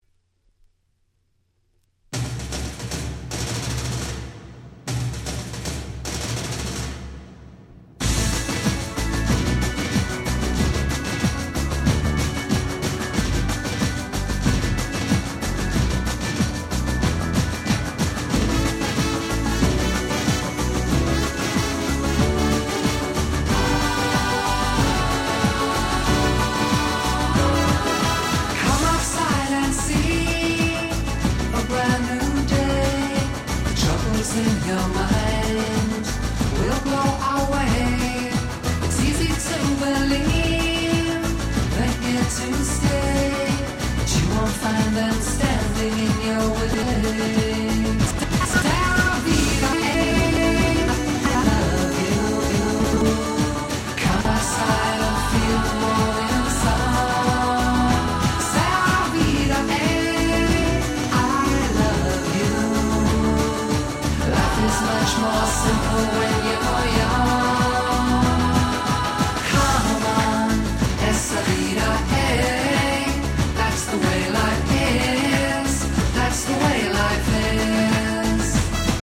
96' 世界的大ヒットPops !!